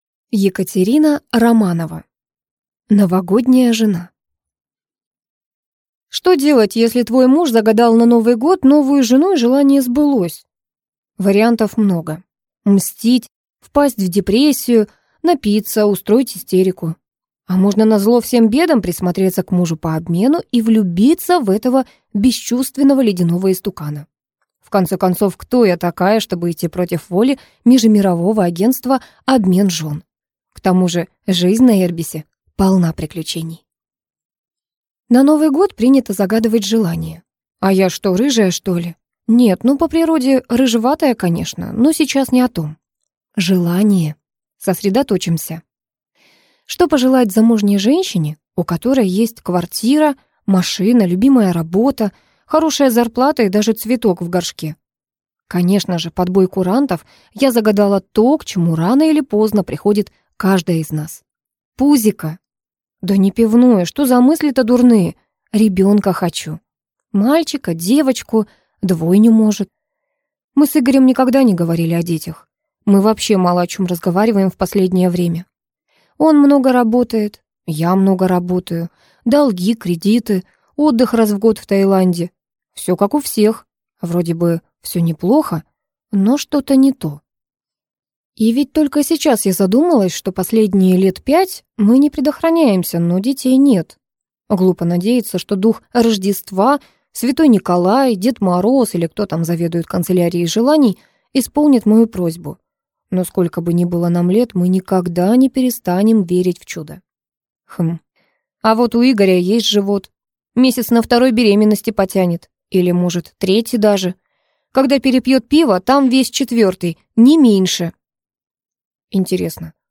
Аудиокнига Новогодняя жена | Библиотека аудиокниг